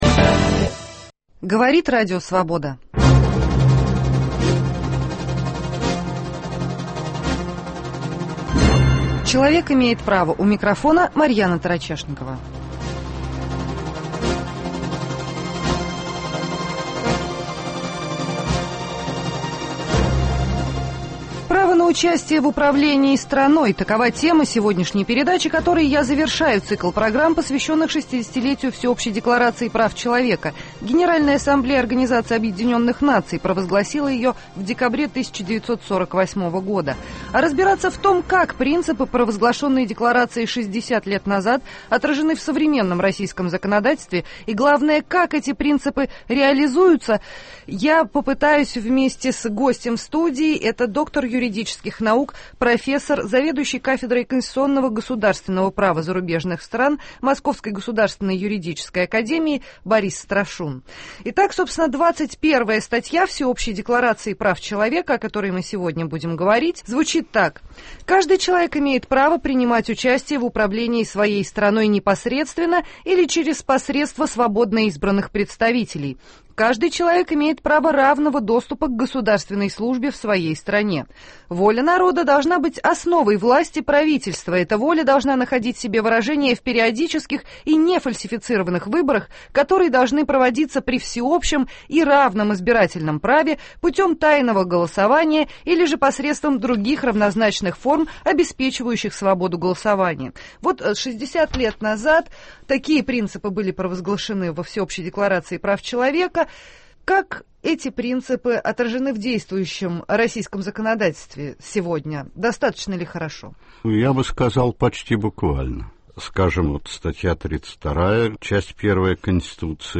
Право граждан на участие в управлении государством. В студии РС